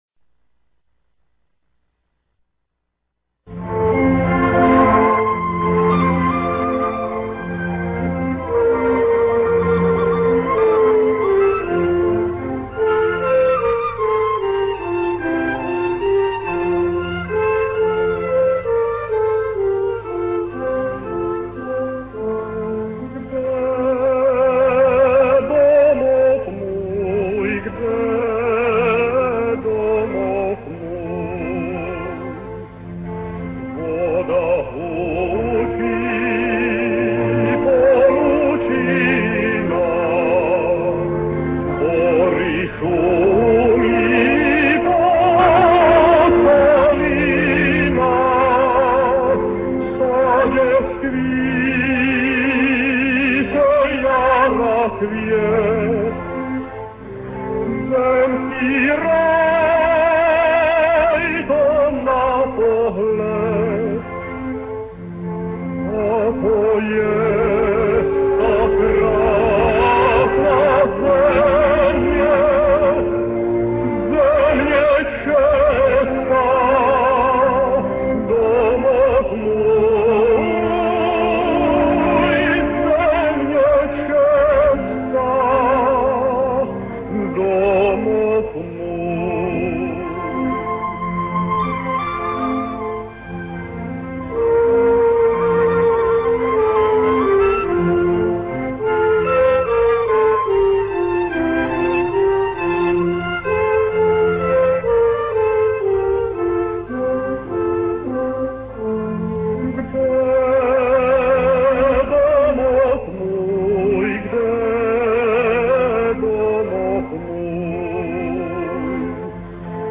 歌唱版